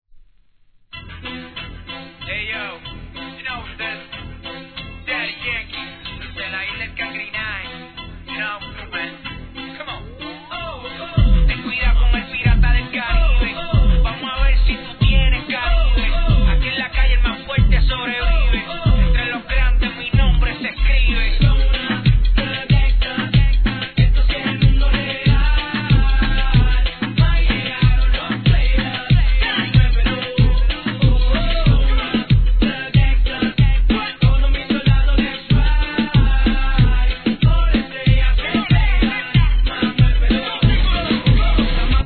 HIP HOP/R&B
こっちもあがります↑ ■REGGAETON